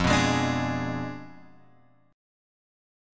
F Diminished 7th